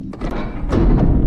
ElevatorPower.mp3